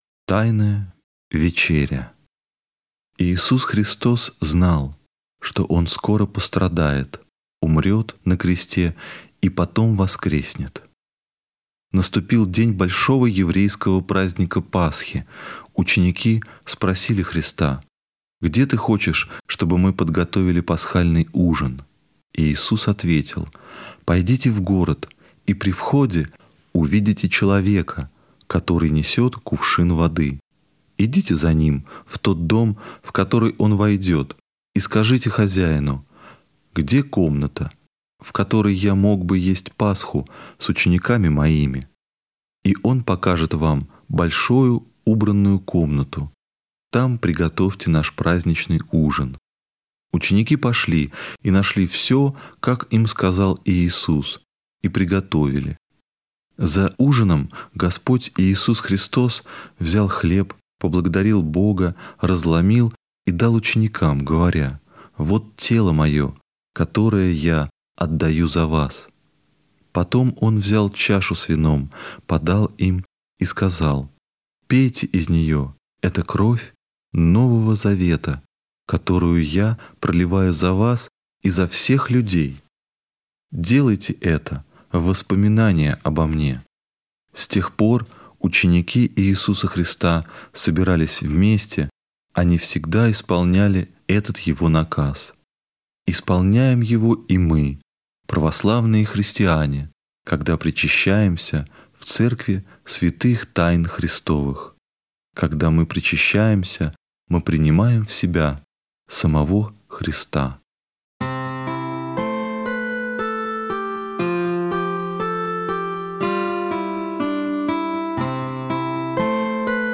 Тайная вечеря - аудио рассказ- слушать онлайн